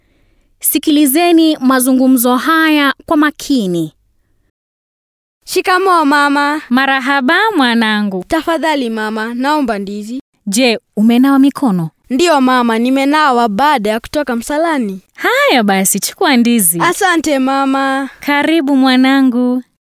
Rekodi 1 Suala kuu la 3 - Mazungumzo.mp3